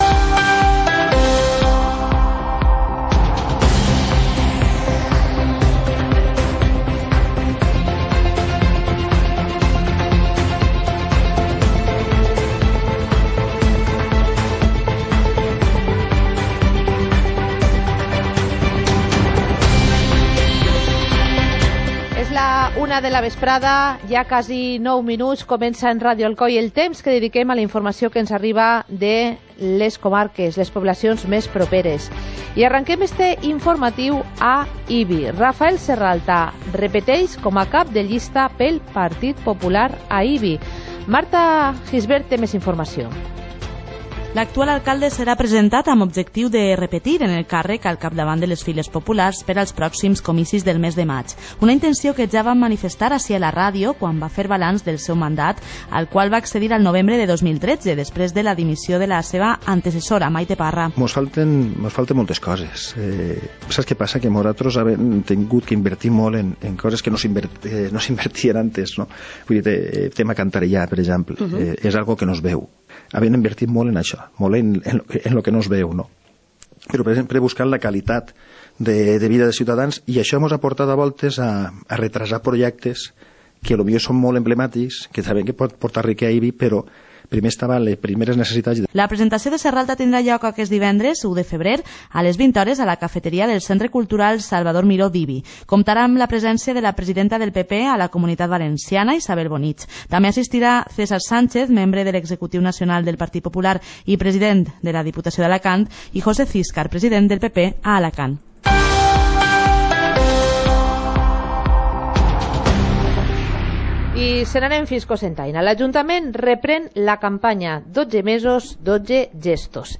Informativo comarcal - jueves, 31 de enero de 2019